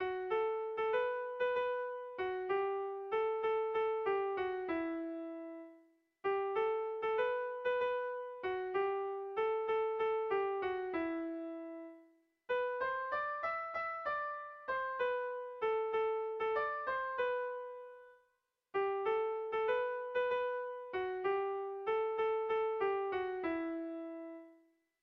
Kontakizunezkoa
Zortziko txikia (hg) / Lau puntuko txikia (ip)
AABA